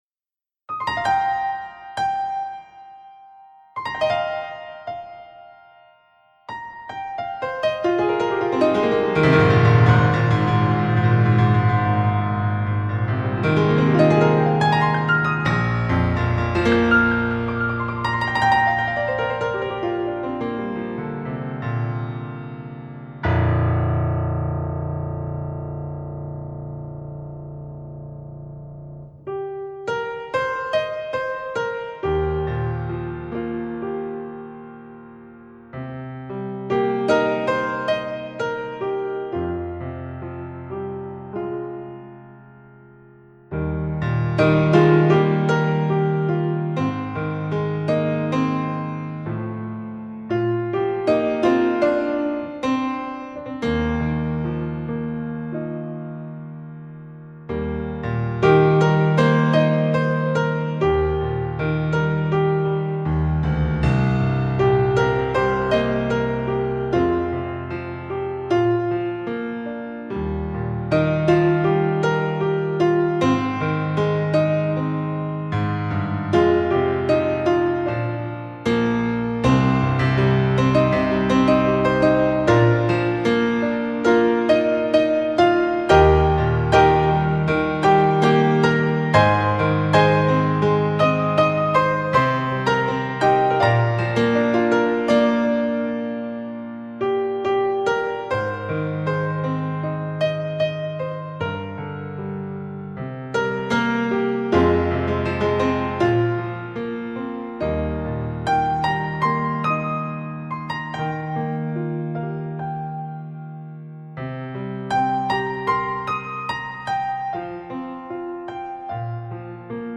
钢琴演奏
是发烧友必备的钢琴录音